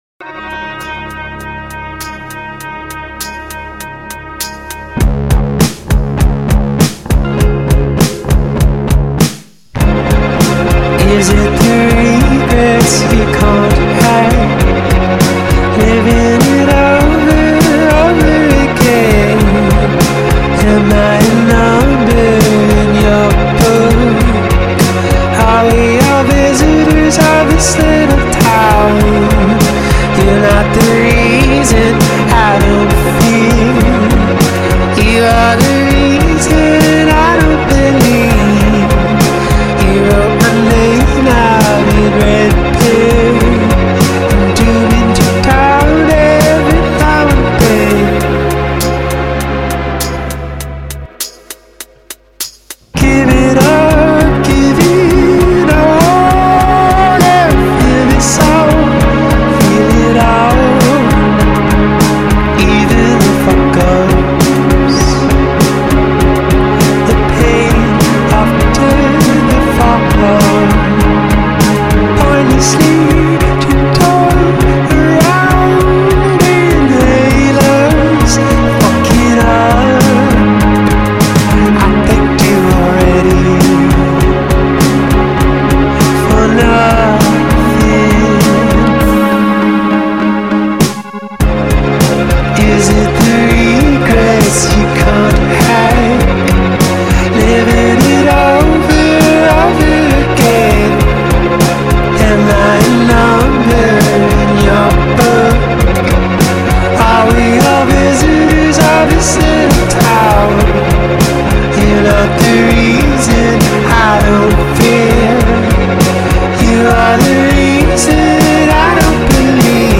alt.pop
Chiming synths usher […]